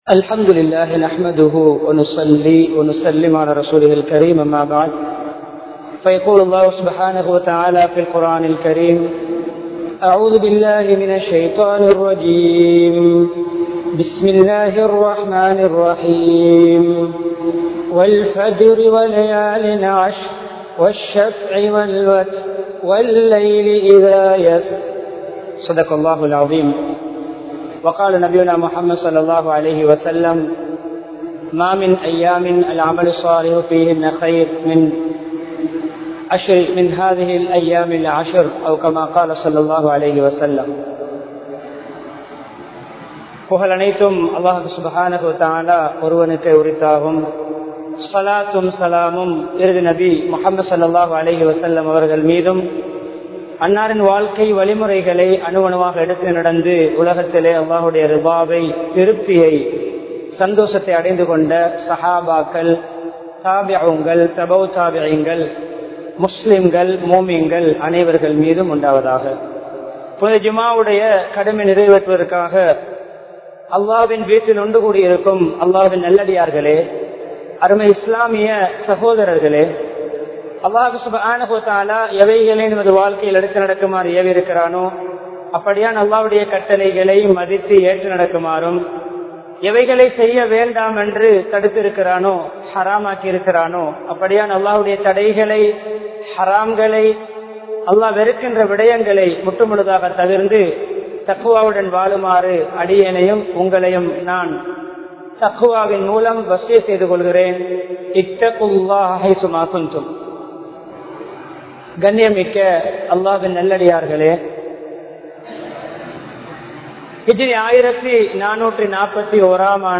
இப்ராஹீம் (அலை) அவர்களது குடும்பத்தின் தியாகங்கள் (Efforts of ibraheem Alaihissalaam's Family) | Audio Bayans | All Ceylon Muslim Youth Community | Addalaichenai
Al Azhar Jumua Masjidh